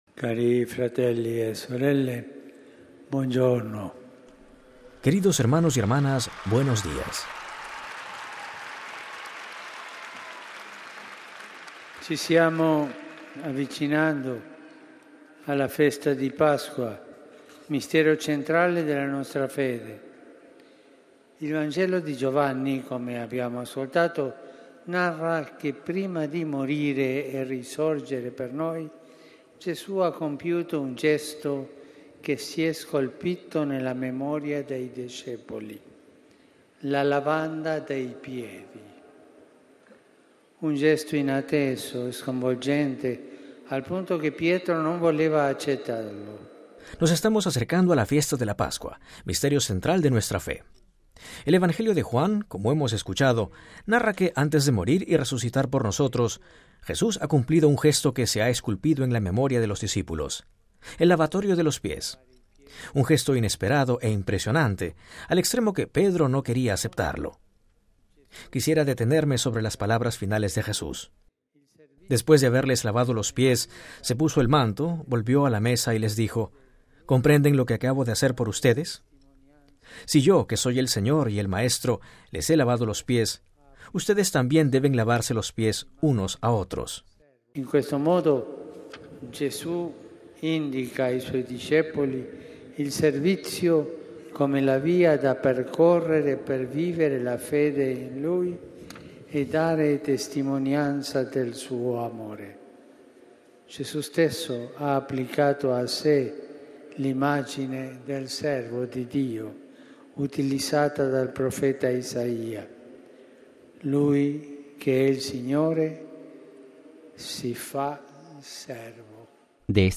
(RV).- “El amor, es el servicio concreto que damos los unos a los otros. Un servicio humilde, hecho en el silencio y en lo escondido, como Jesús mismo nos ha mostrado”, lo dijo el Papa Francisco en la catequesis de la Audiencia Jubilar del segundo sábado de marzo, donde explicó la relación entre “misericordia y servicio”.
Texto y audio completo de la catequesis del Papa Francisco